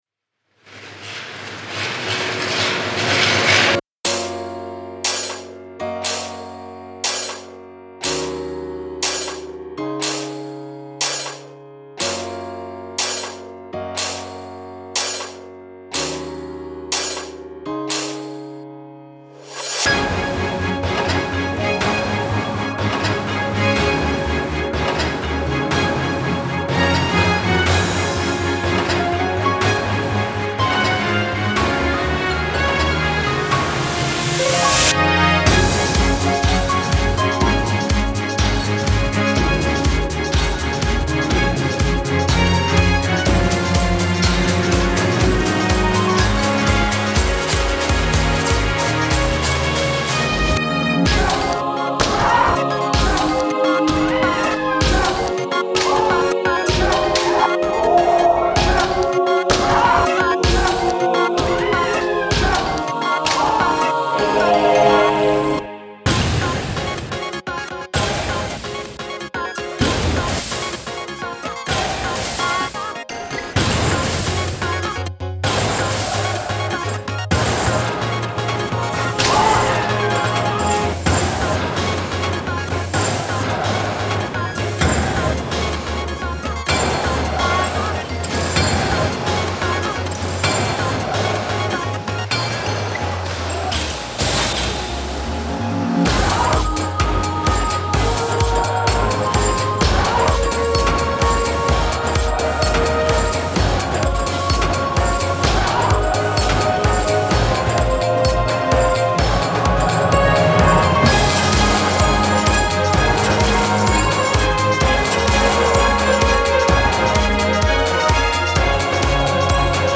特殊鋼が生まれる様子を描いたシンフォニー。
これらを組み合わせてひとつの音楽にしました。